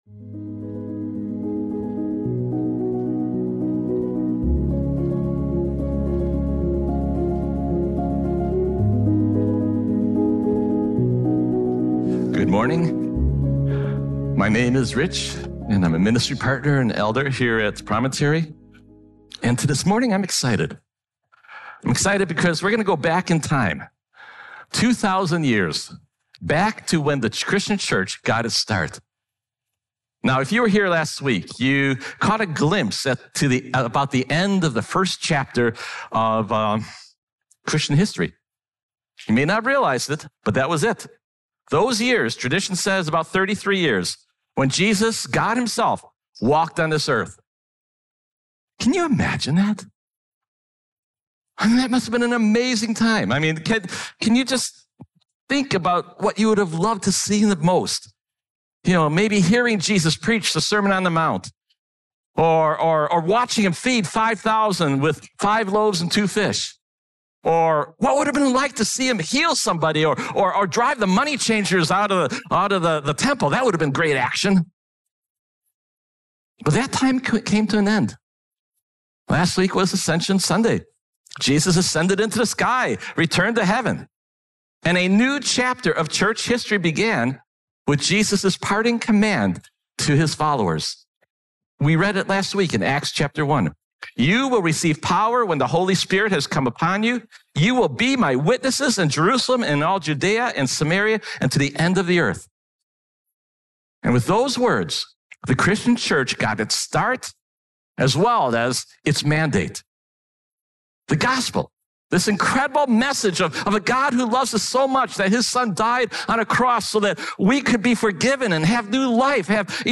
Stand-alone Sermons